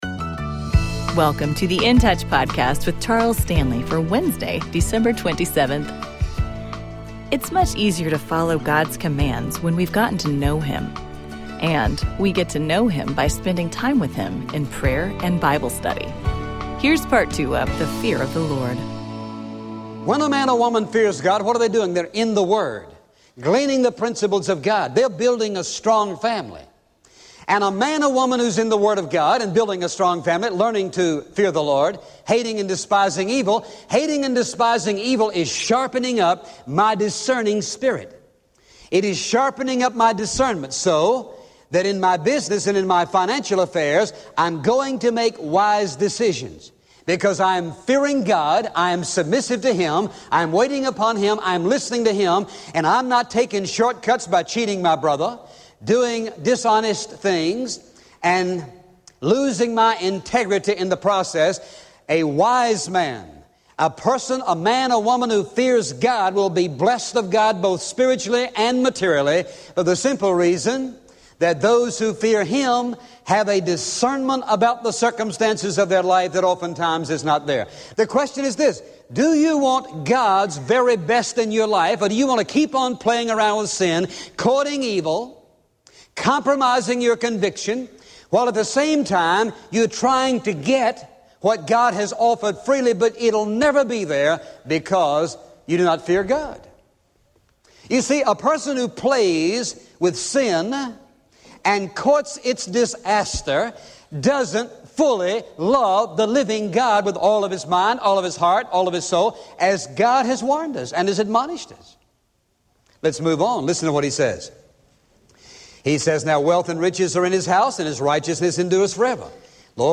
Daily Radio Program